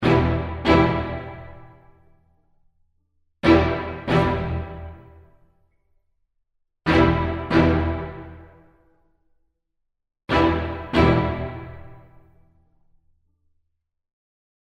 So, first I will load the strings and I’ll have them play an ordinary E-minor chord.
OrchestralHits_Strings.mp3